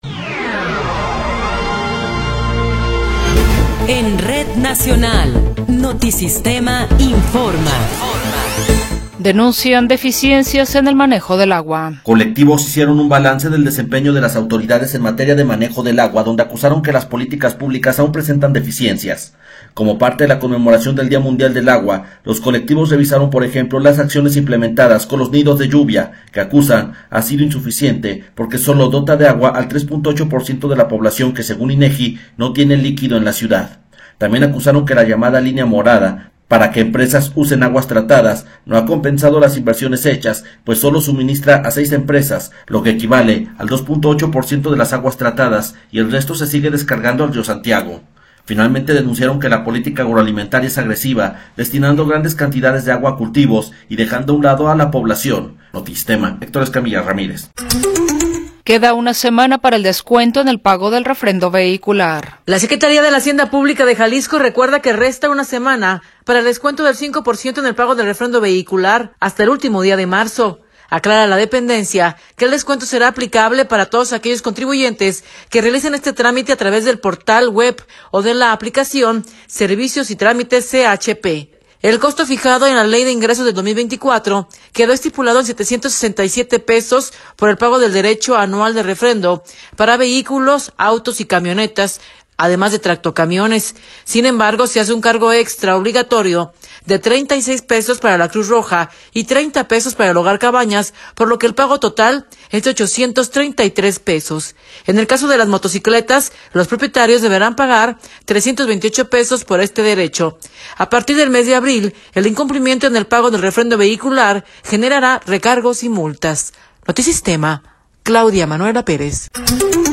Noticiero 16 hrs. – 22 de Marzo de 2024
Resumen informativo Notisistema, la mejor y más completa información cada hora en la hora.